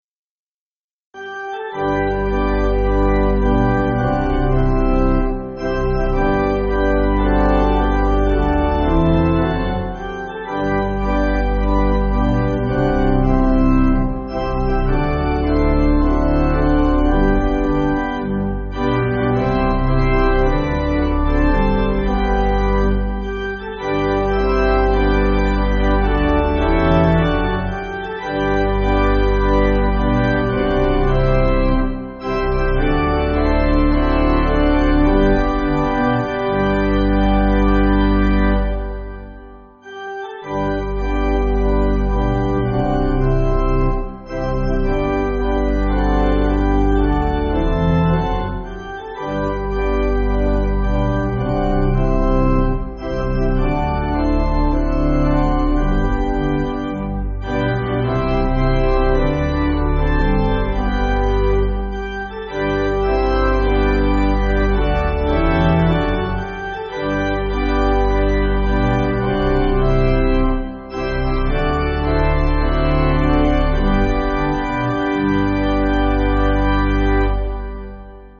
Organ
(CM)   3/G